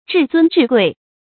至尊至贵 zhì zūn zhì guì
至尊至贵发音